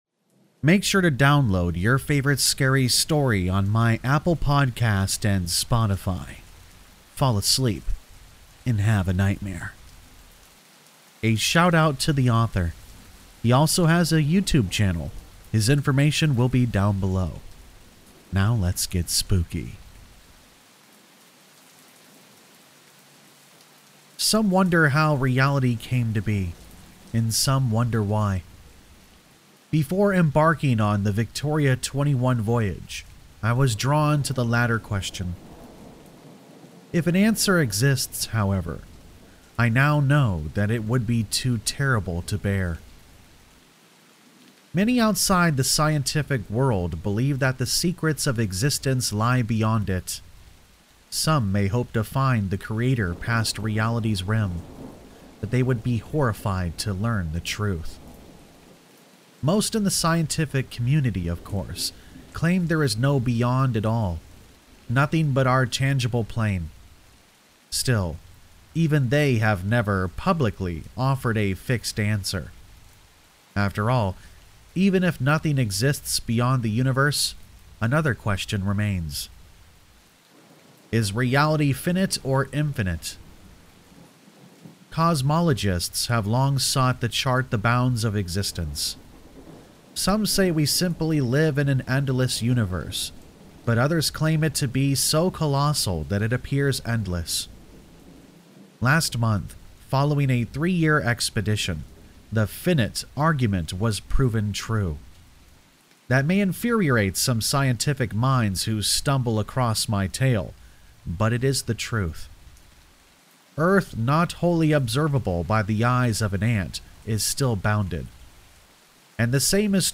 Allegedly True Scary Stories and Creepypastas